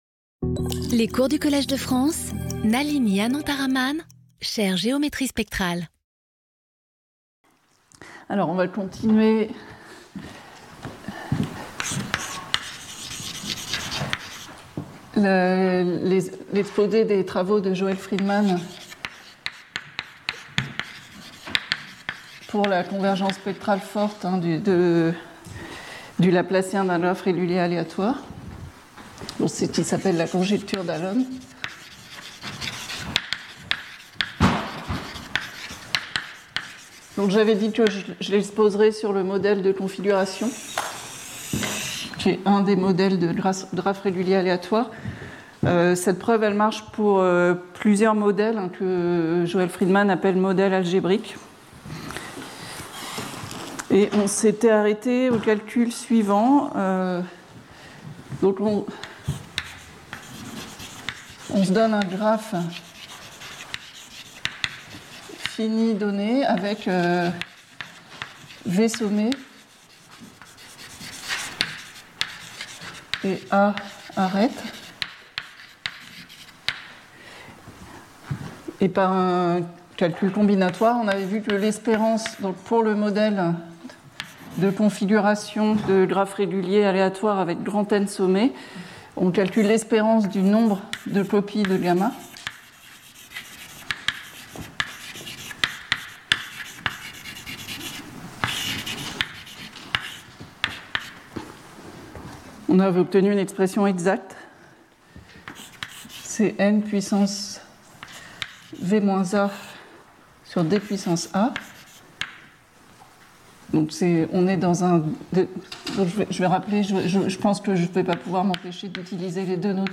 Intervenant(s) Nalini Anantharaman Professeure du Collège de France
Cours